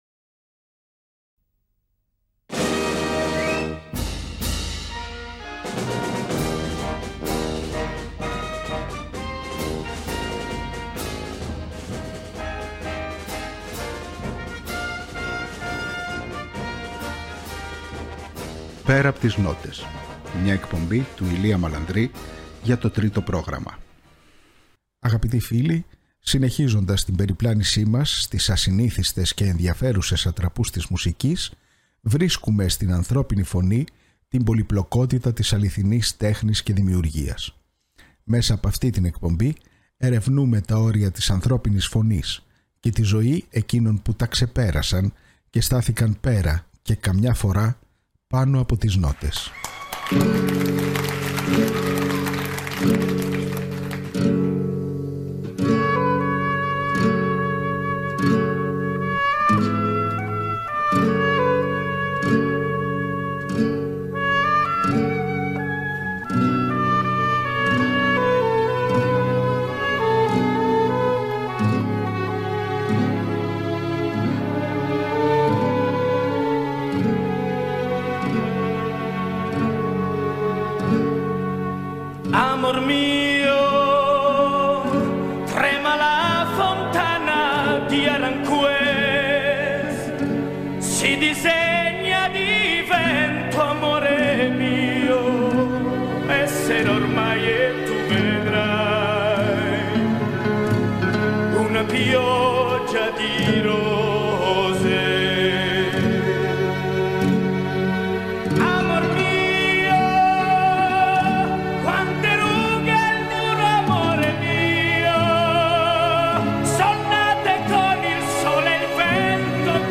Ακούστε στην εκπομπή της Παρασκευής 22 Μαρτίου το 2ο μέρος ενός αφιερώματος στη ζωή του σπουδαίου καλλιτέχνη, που μεσουρανεί ακόμα και σήμερα, με ένα σπάνιο υλικό που δεν έχει δει το φως της δημοσιότητας εδώ και 50 χρόνια.